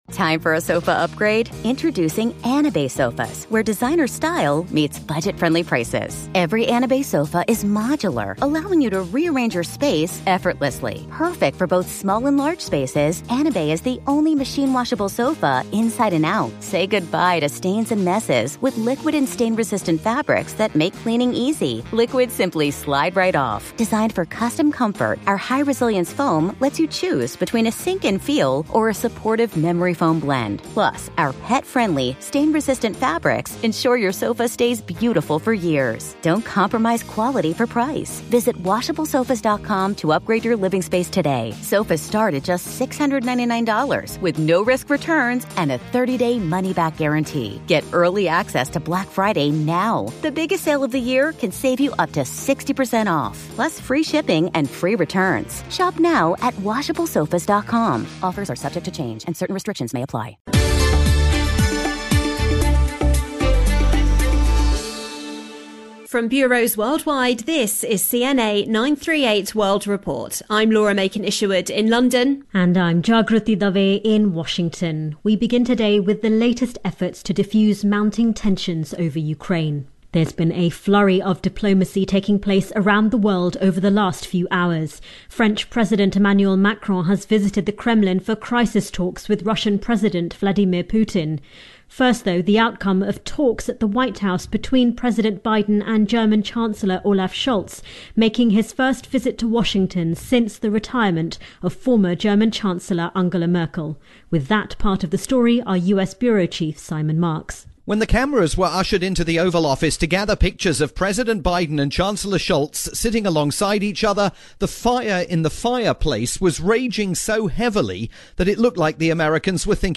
report for FSN's daily "World Report" programme airing on CNA 938 in Singapore.